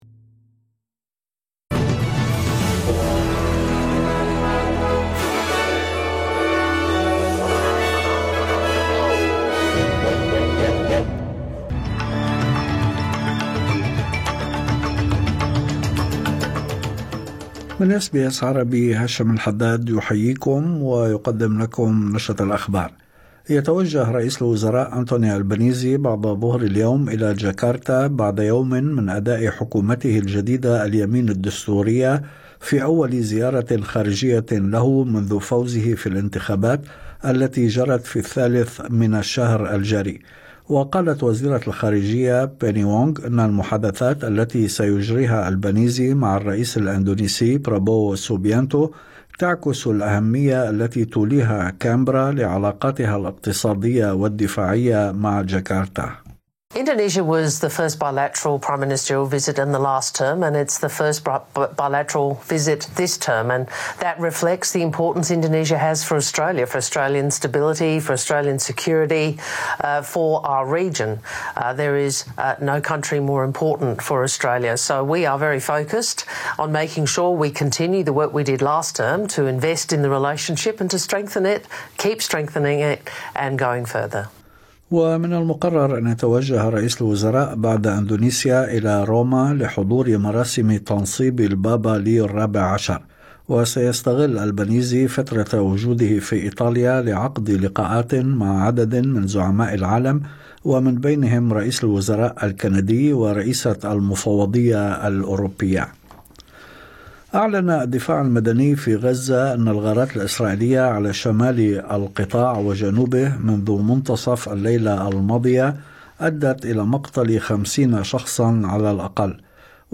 نشرة أخبار الظهيرة 14/5/2025